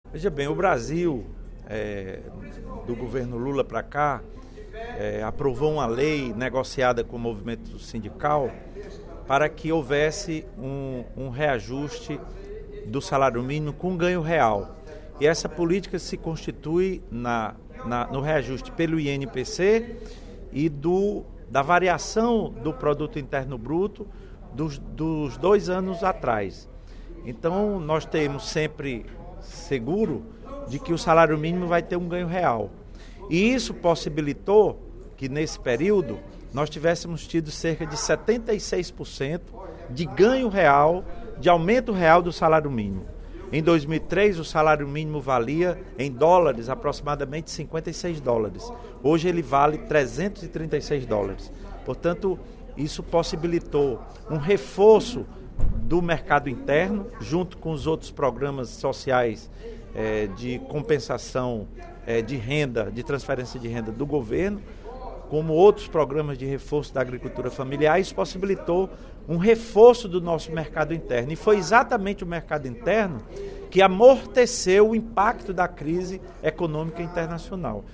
Durante o primeiro expediente da sessão plenária desta quinta-feira (13/03), o deputado Lula Morais (PCdoB) destacou a importância da manutenção do aumento real do salário mínimo (SM).